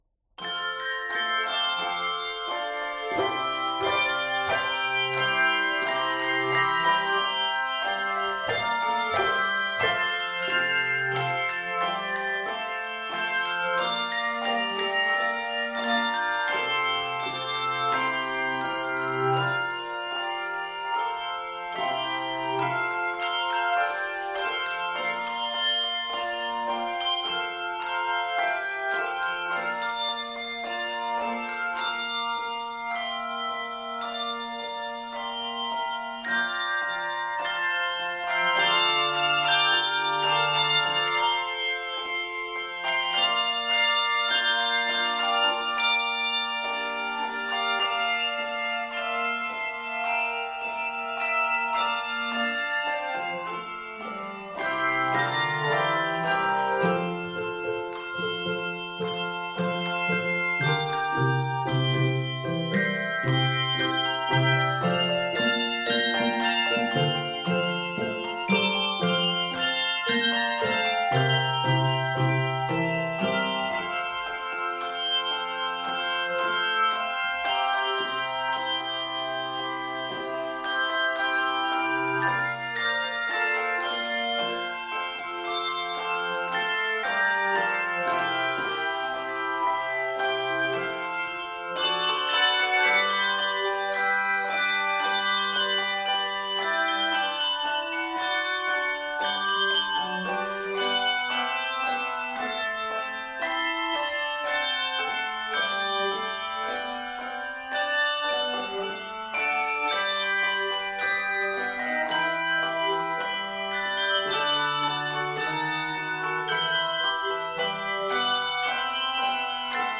this deeply moving piece